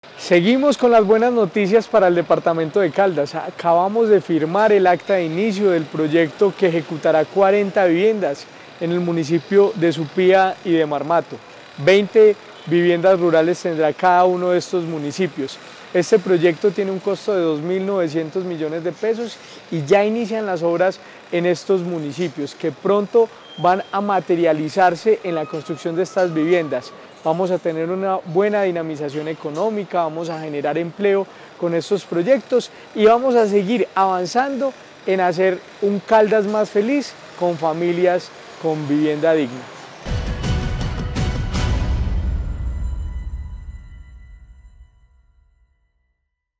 Jorge William Ruiz Ospina, secretario de Vivienda y Territorio de Caldas.